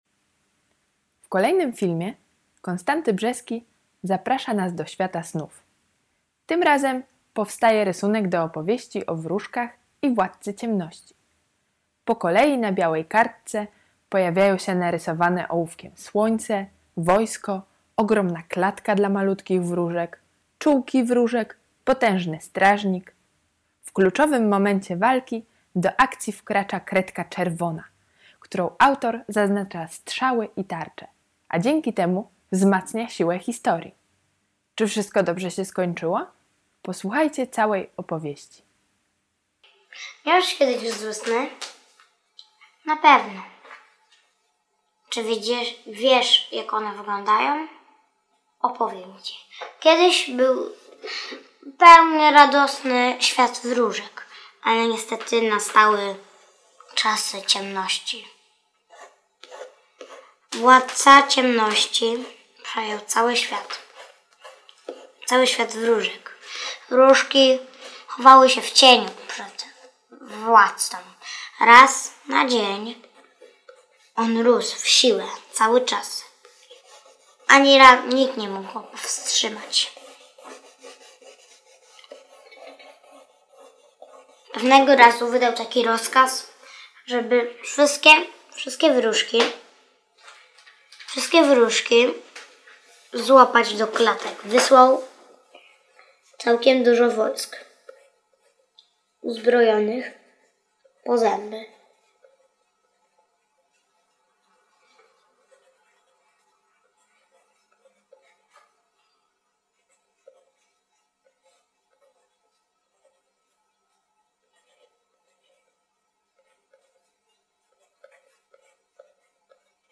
audiodeskrypcja